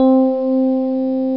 E Piano Hi Sound Effect
Download a high-quality e piano hi sound effect.
e-piano-hi.mp3